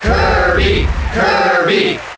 Kirby's Melee Crowd Chant (NTSC) You cannot overwrite this file.
Kirby_Cheer_International_SSBM.ogg